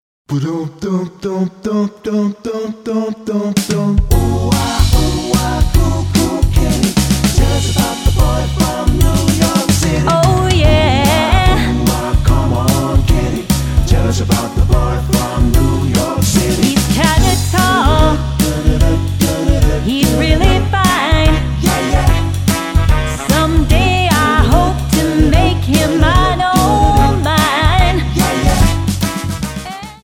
--> MP3 Demo abspielen...
Tonart:F# Multifile (kein Sofortdownload.